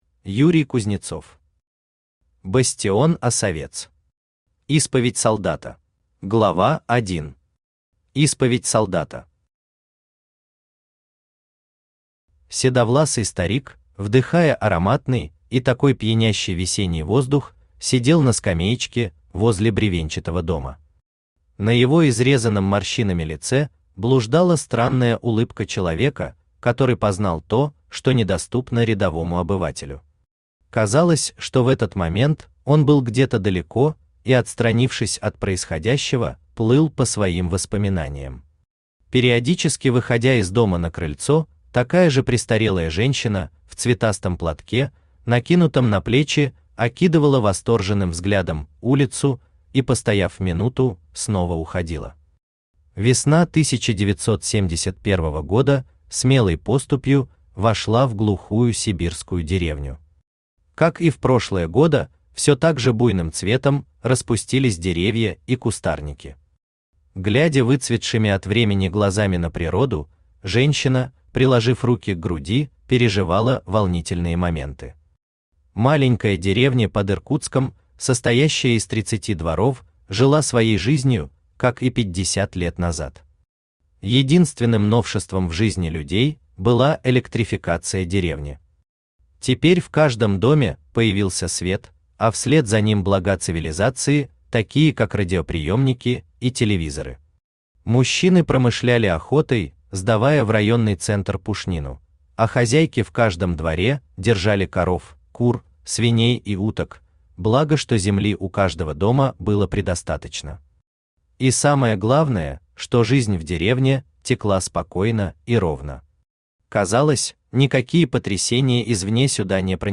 Аудиокнига Бастион Осовец. Исповедь солдата | Библиотека аудиокниг
Исповедь солдата Автор Юрий Юрьевич Кузнецов Читает аудиокнигу Авточтец ЛитРес.